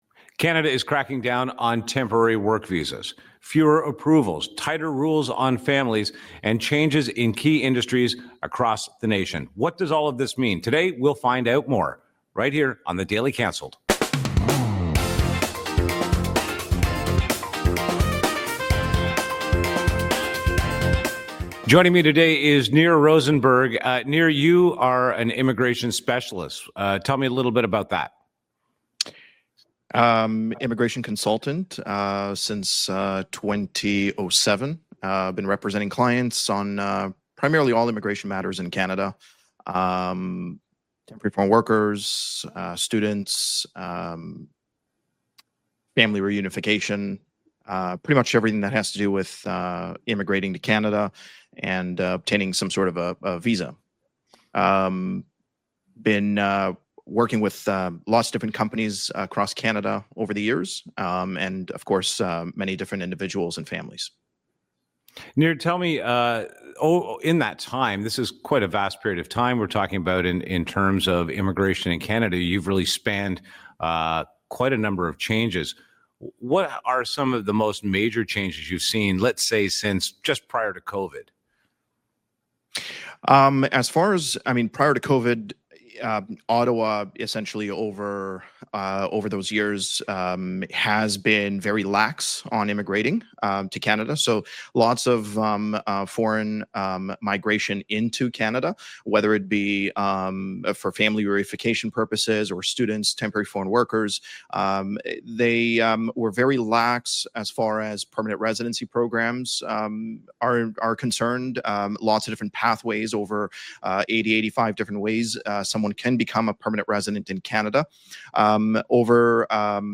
… continue reading 25 episodes # Daily News # Politics # News Talk # News # True Patriot Love